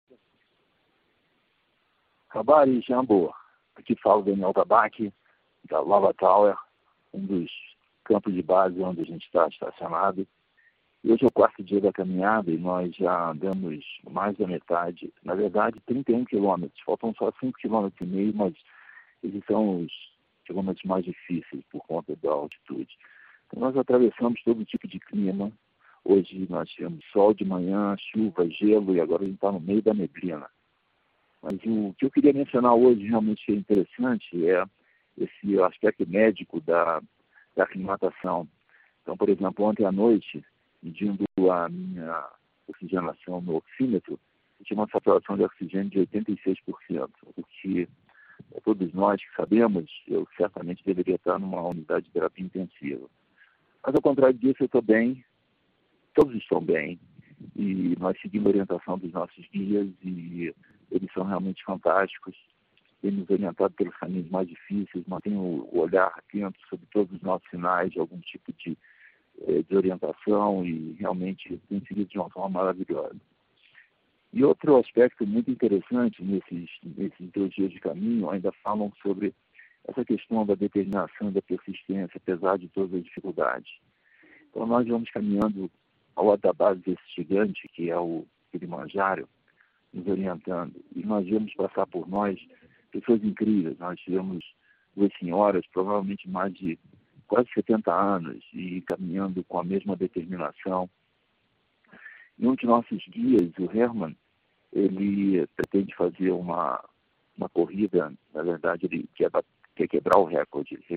Today is the fourth day of the walk and we have walked more than half, actually 31 kms, it is missing only 5.5 kms, but they are the toughest ones because of the altitude.